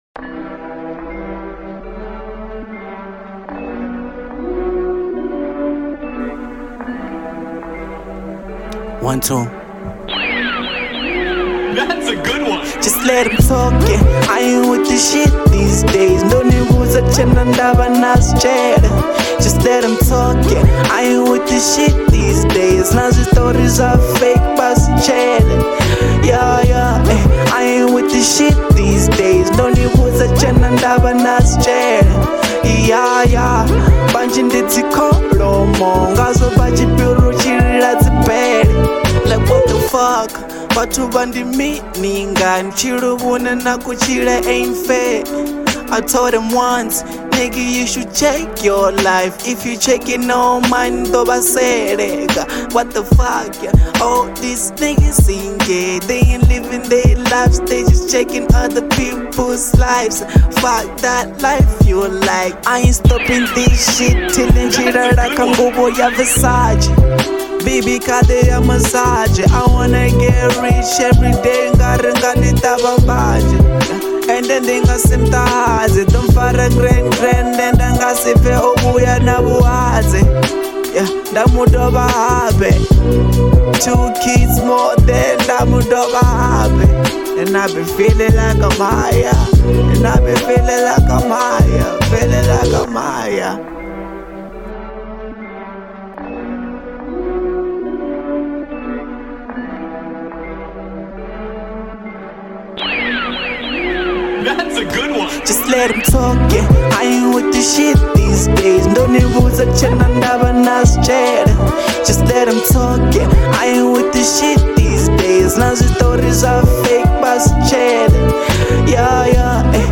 Genre : Venrap